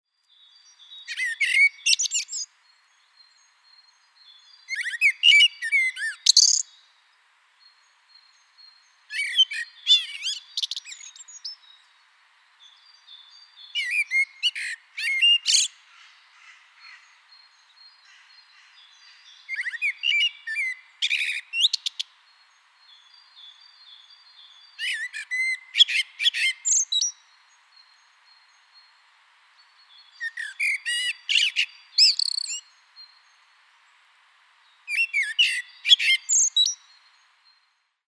Digiloto birdsong game - learn common Estonian bird sounds through play.